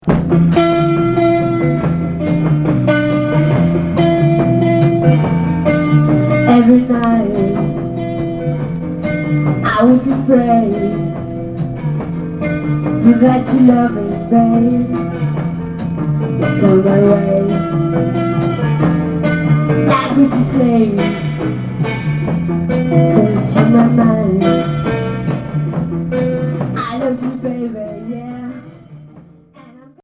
Garage